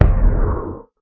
elder_hit4.ogg